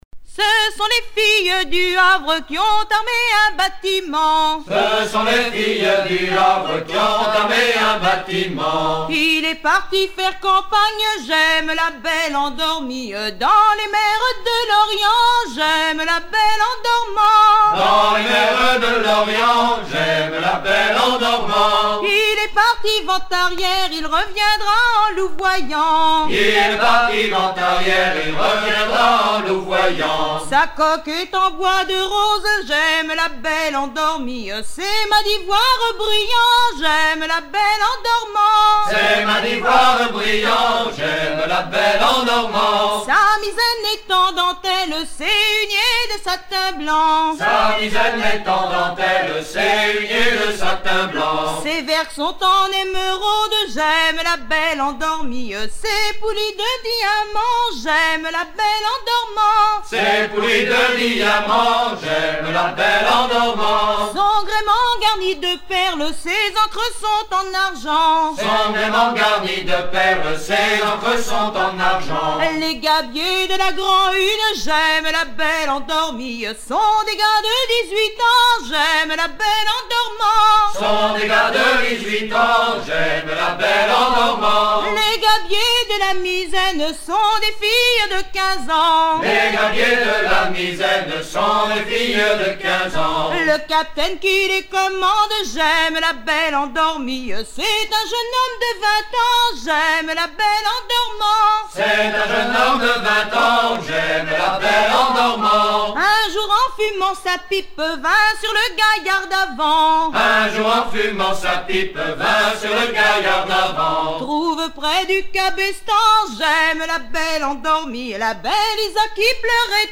danse : ronde
Pièce musicale éditée